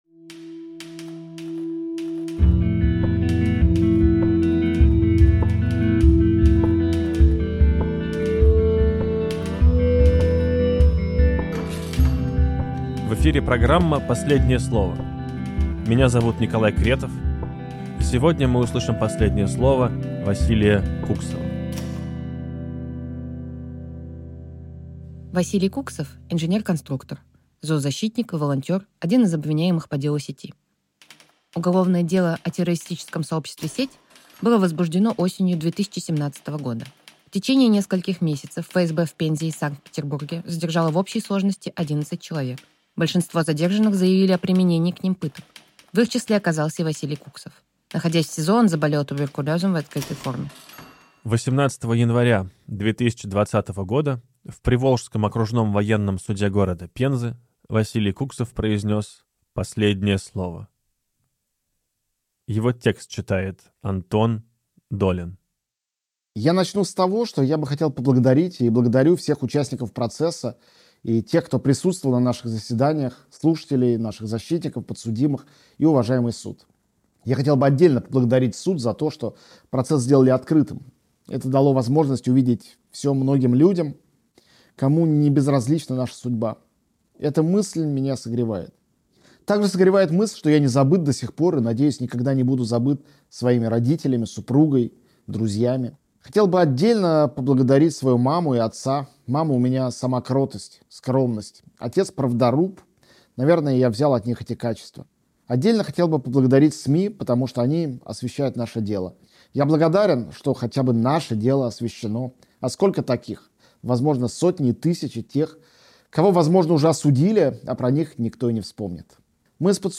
Читает Антон Долин.